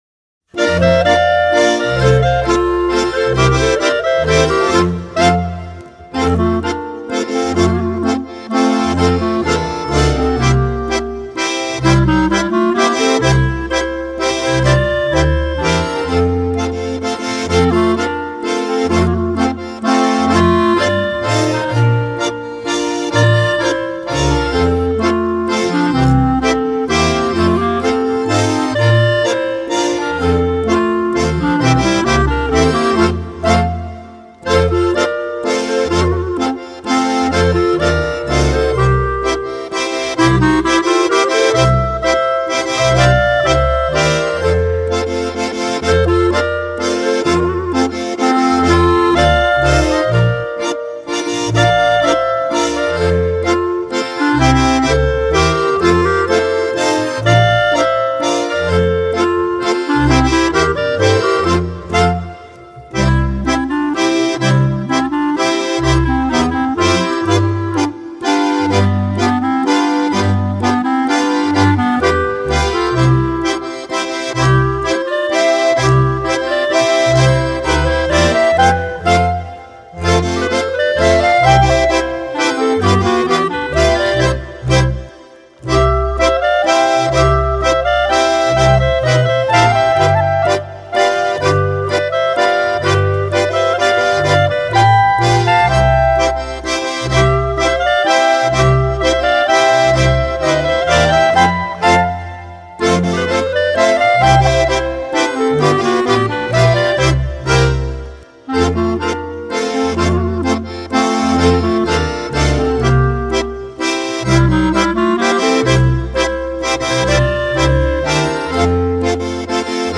Mazurka.